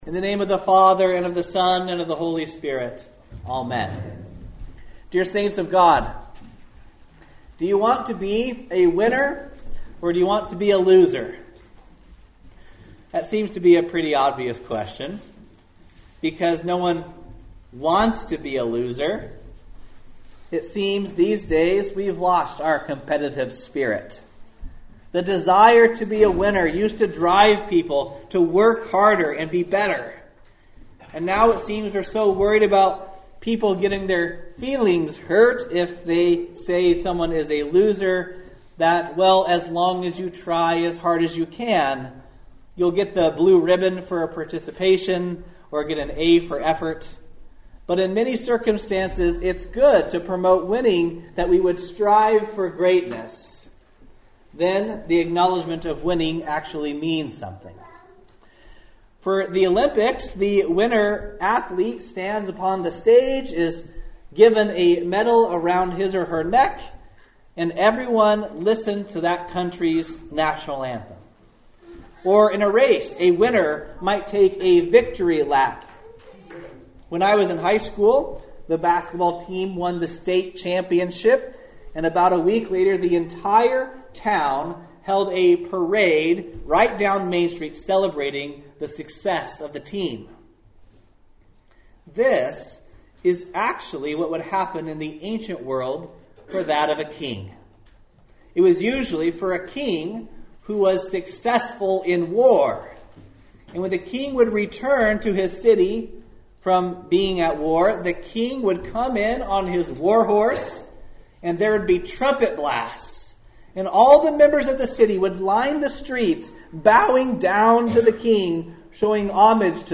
2018-palmsunday.mp3